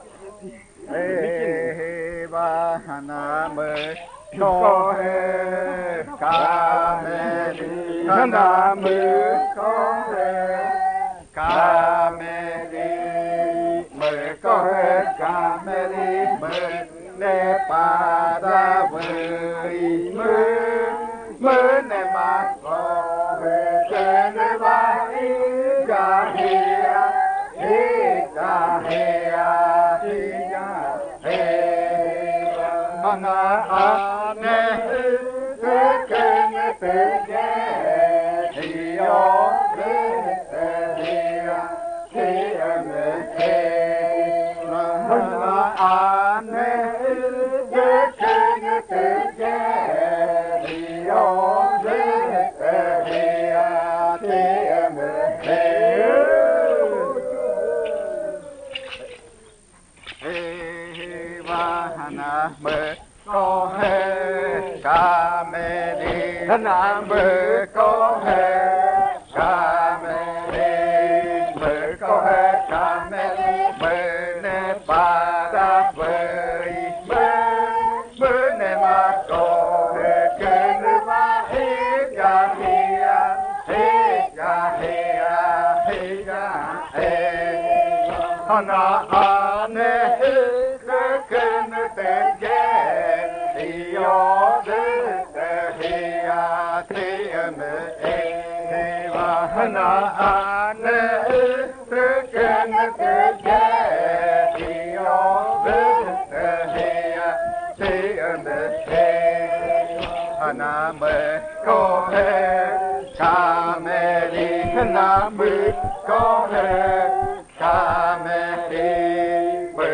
27. Baile de nombramiento. Canto n°21
Puerto Remanso del Tigre, departamento de Amazonas, Colombia